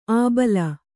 ♪ ābala